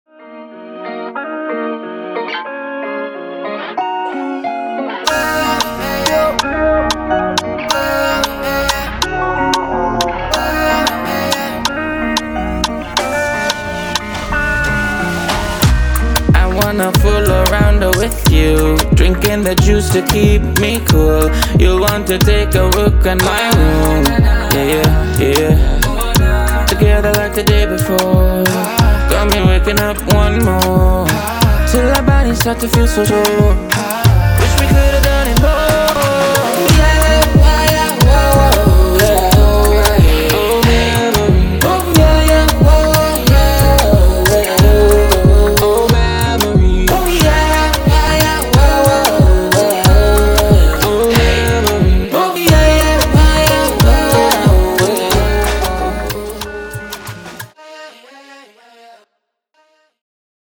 Afropop & Afrobeats
36 drum & percussion loops
71 guitar loops
1 karimba loop
27 vocal loops